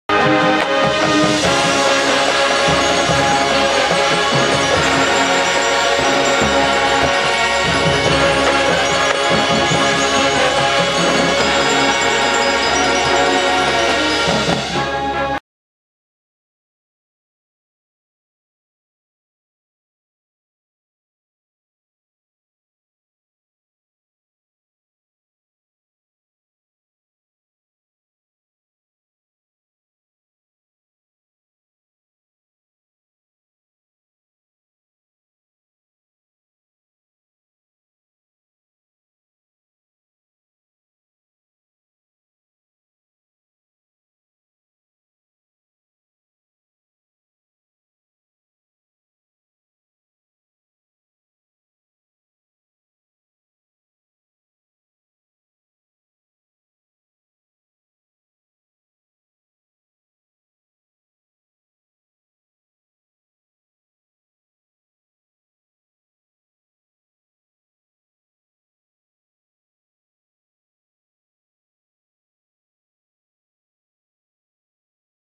This page contains downloadable files of band and colorguard performances.
Marching Band - Field Shows